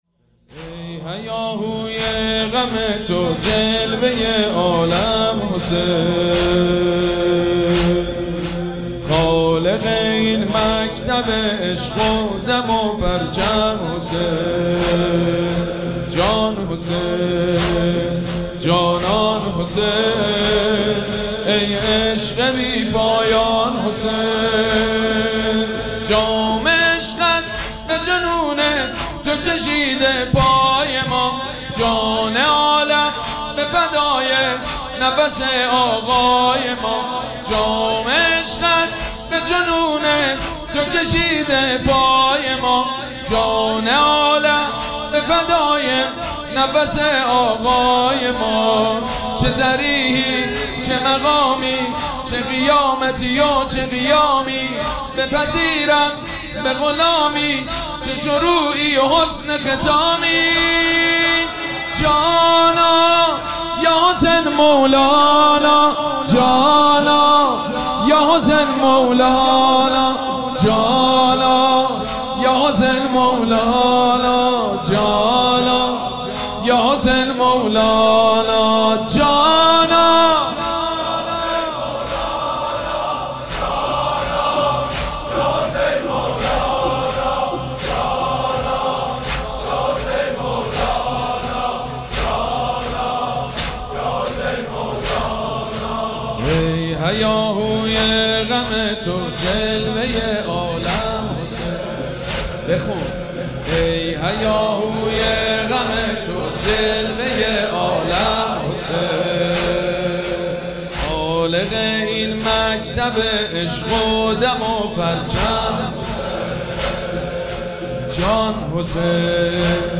مراسم عزاداری شهادت امام محمد باقر و حضرت مسلم سلام‌الله‌علیهما
مداح
حاج سید مجید بنی فاطمه